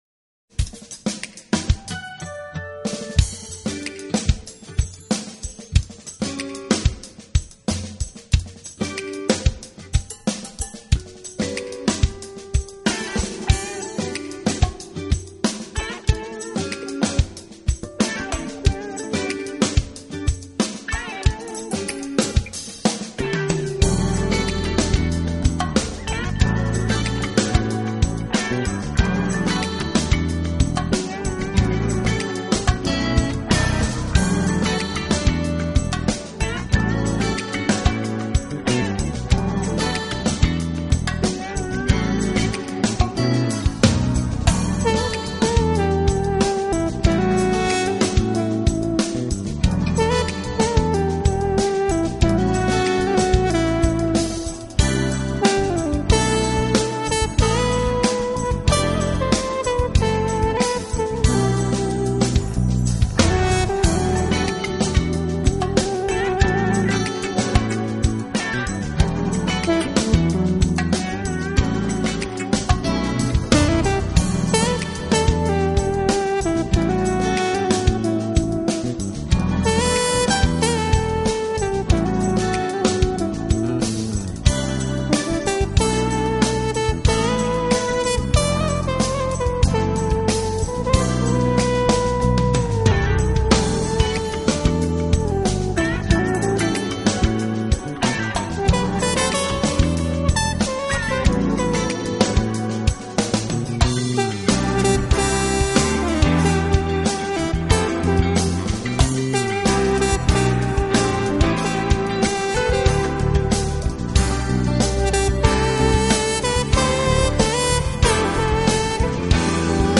Genre: Chillout / Christmas / Classical / Instrumental